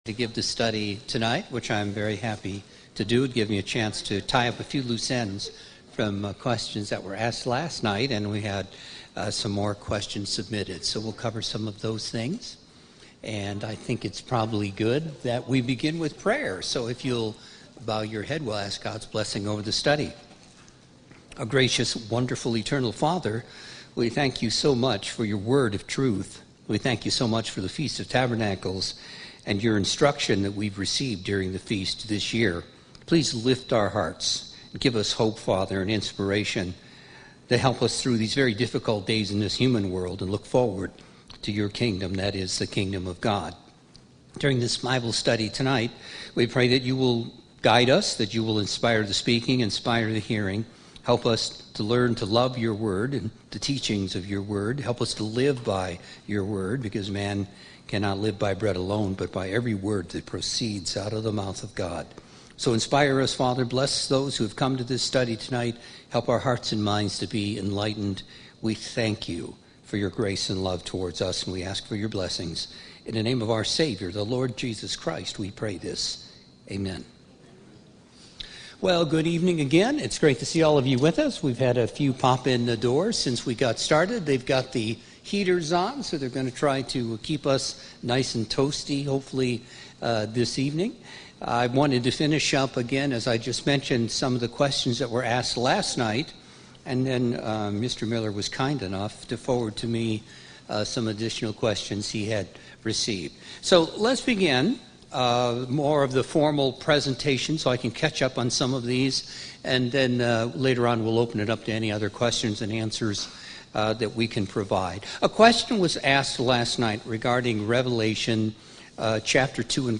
Bible Study: Meaning of the Book of Galatians Part II
Bible Study: Meaning of the Book of Galatians Part II 10/8/2020 - Feast of Tabernacles Berlin, OH 2020